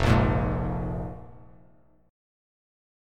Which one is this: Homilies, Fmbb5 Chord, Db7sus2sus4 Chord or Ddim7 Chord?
Fmbb5 Chord